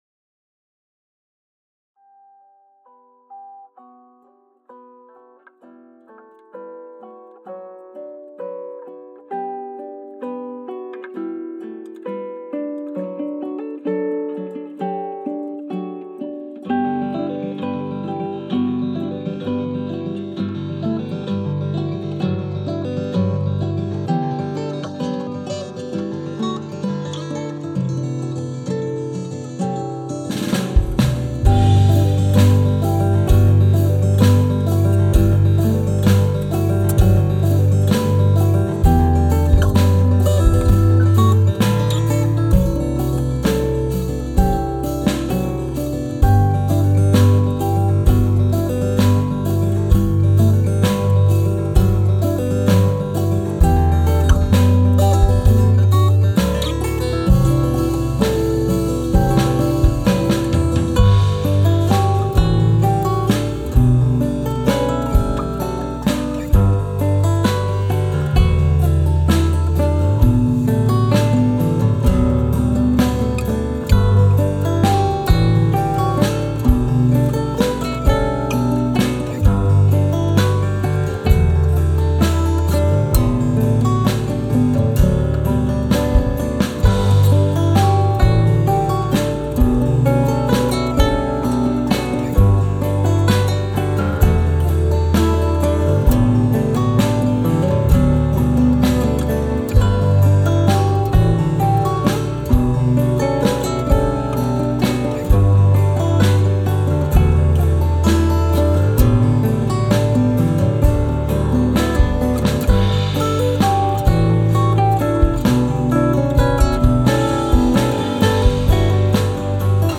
guitaristes